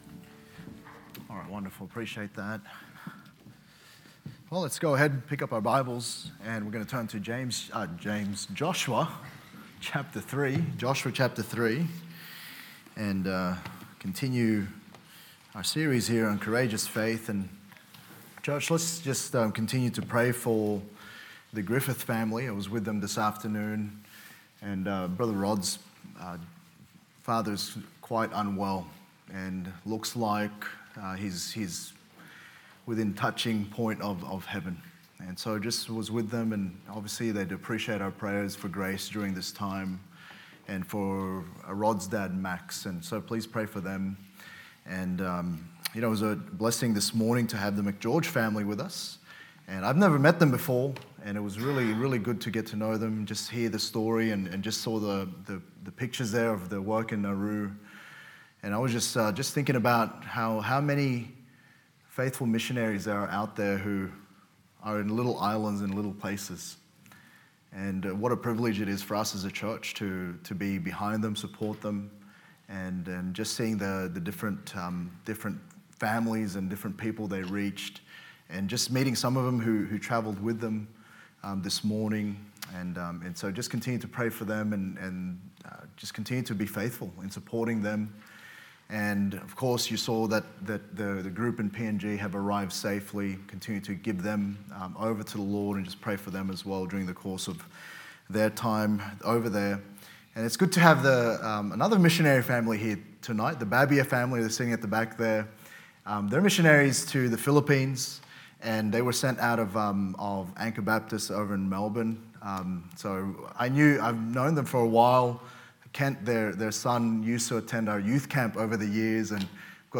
Sermons | Good Shepherd Baptist Church
Sunday PM